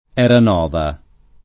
[ H ran 0 va ]